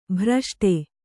♪ bhraṣṭe